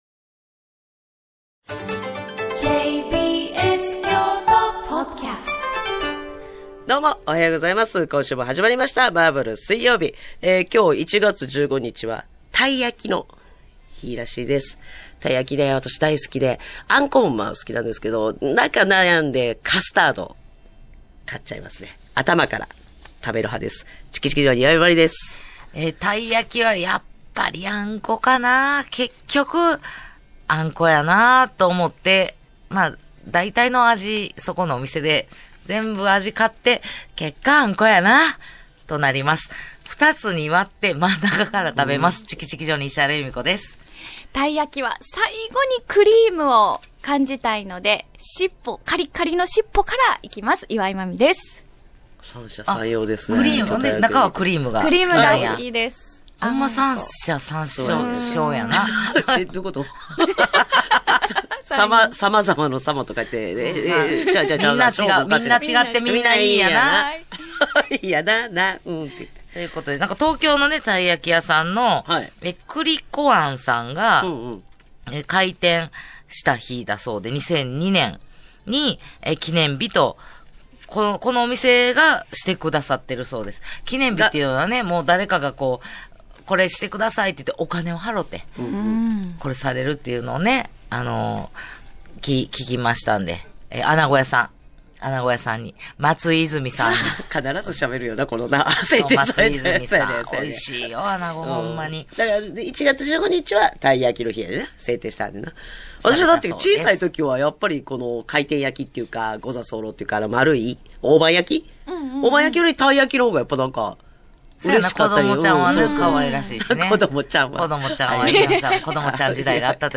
【KBS京都ラジオ 毎週水曜日 10:00〜14:00 オンエア】アラサー・アラフォーの独身女子たちが送るバラエティラジオ。グルメや旅行の話題から、今すぐ言いたいちょっとした雑学、みんな大好き噂話まで気になる話題が満載。